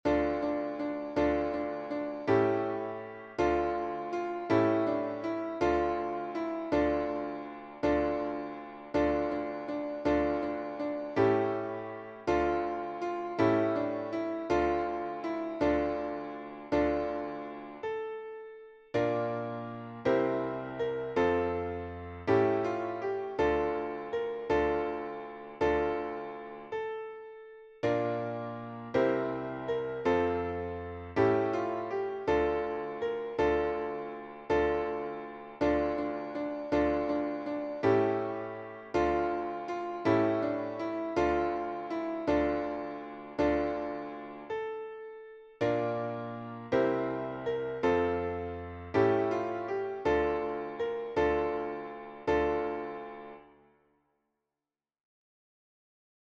Hebrew round